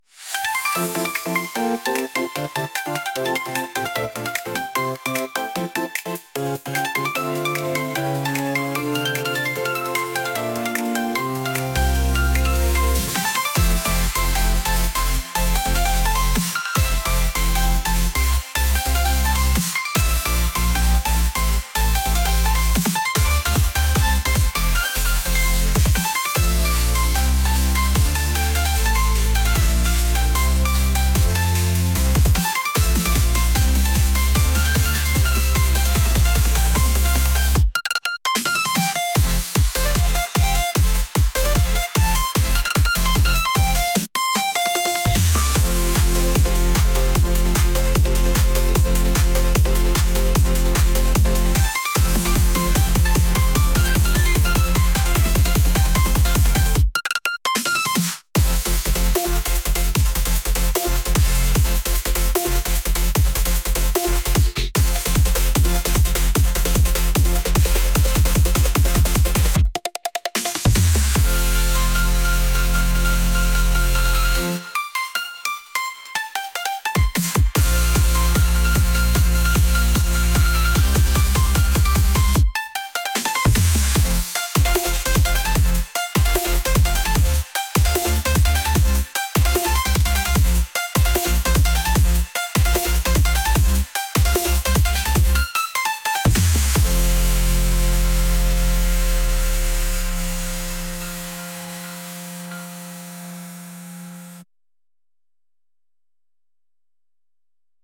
upbeat | energetic